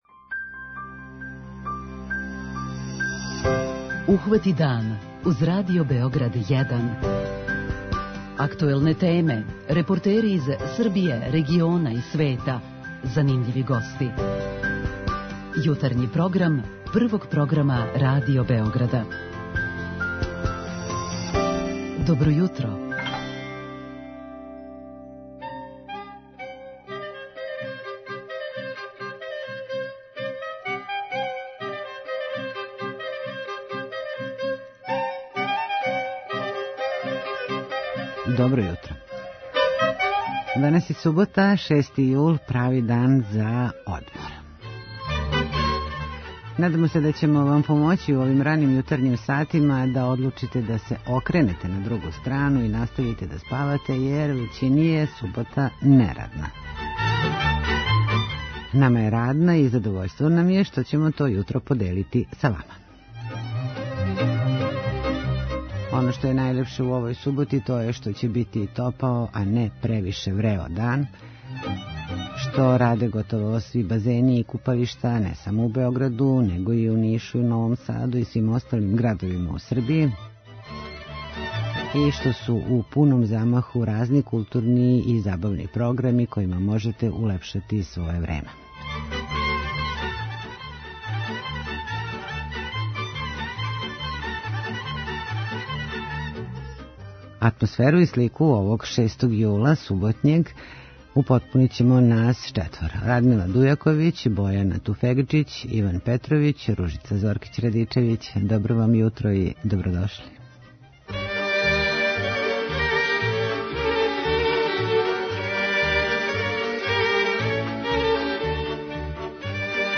У току је EXIT у Новом Саду, а у Београду - пројекције филмова на отвореном. Наши репортери доносе приче и из Чачка, Зрењанина, Јагодине...